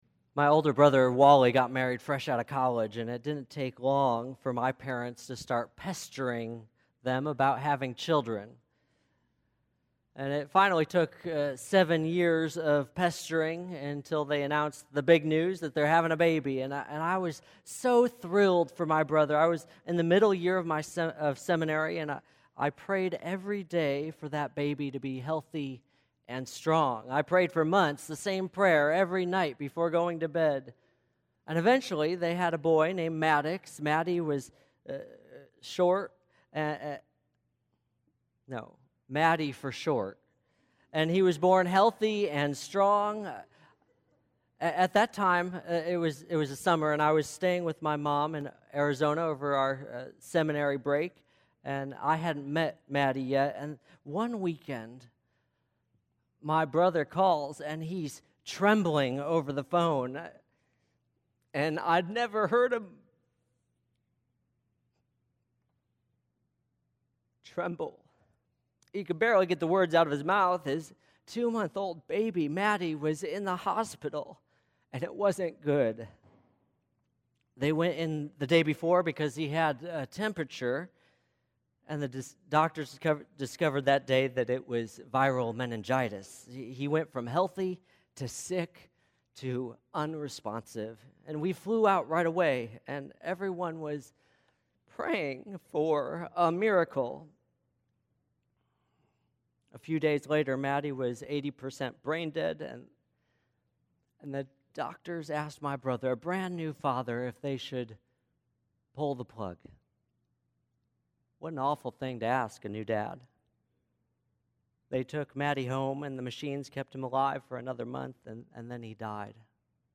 All Saints Sunday Sermon